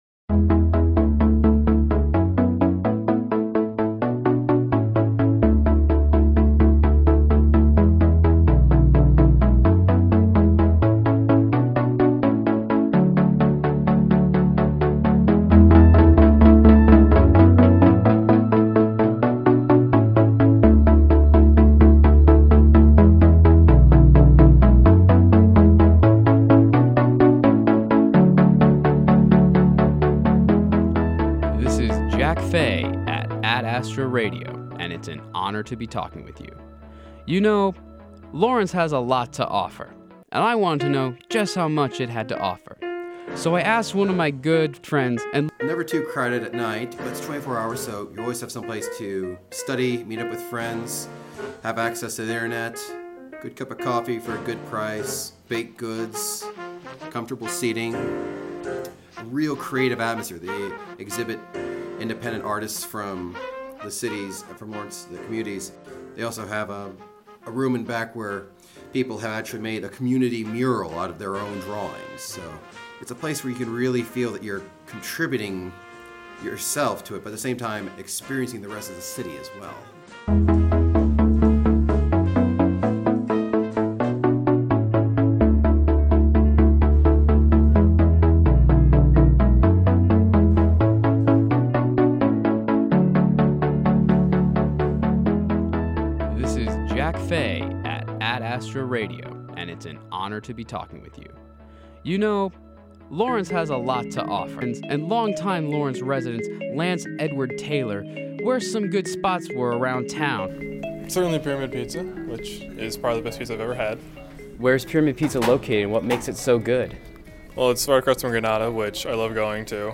First year experience was originally aired on Ad Astra Radio Aug. 30